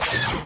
shot19.mp3